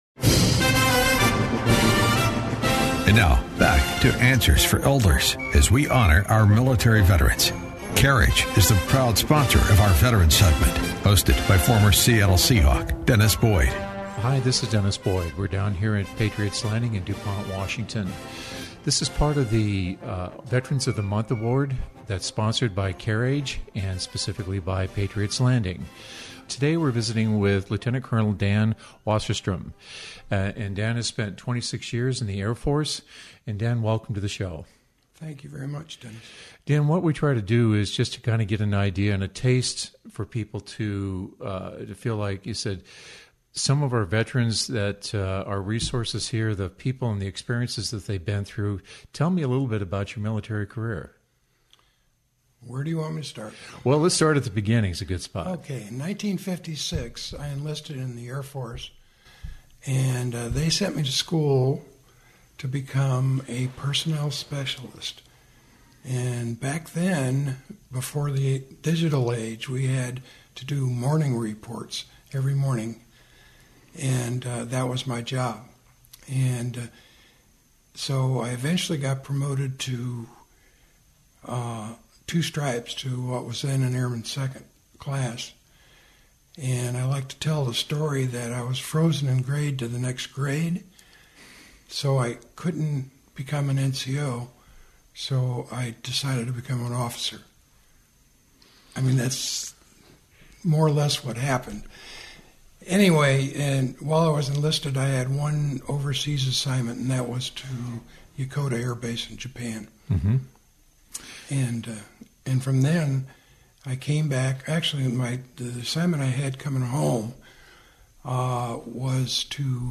Veterans Interview